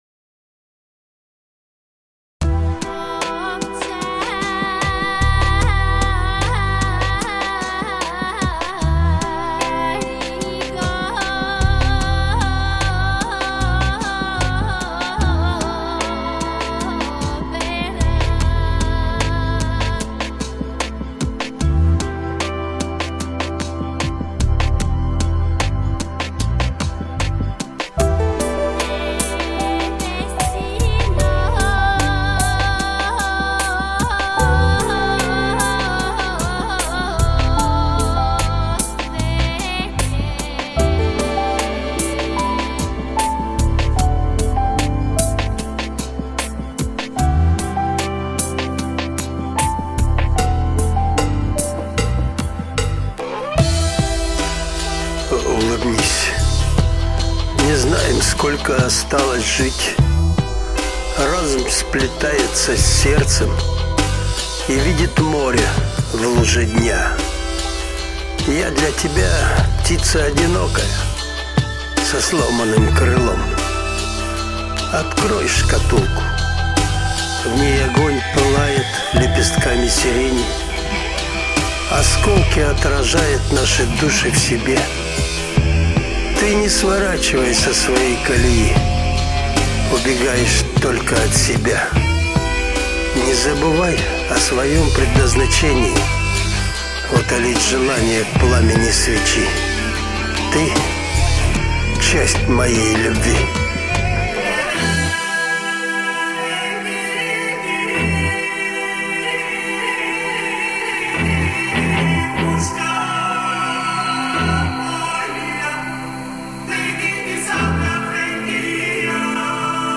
mp3,4584k] Рок